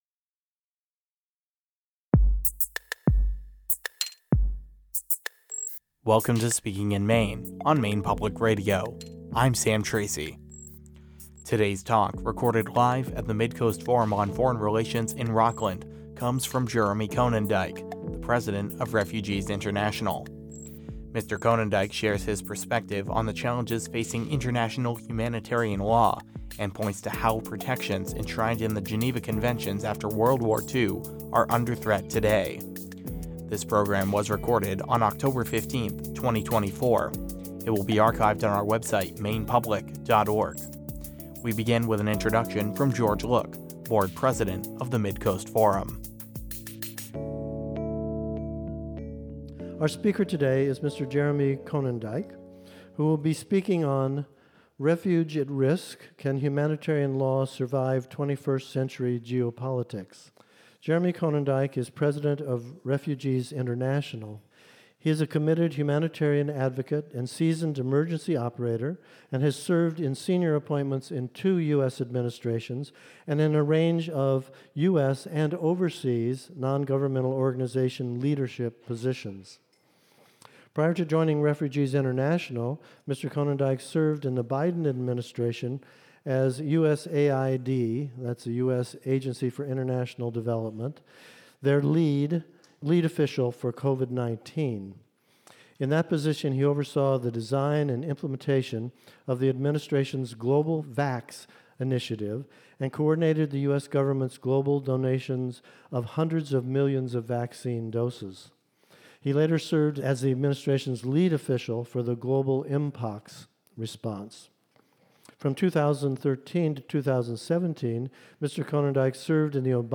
Shrinking Interview